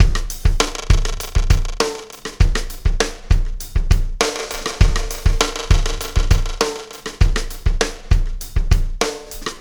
Pulsar Beat 11.wav